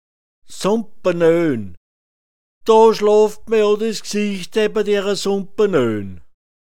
Sumpernelle [sumpa’nεn] f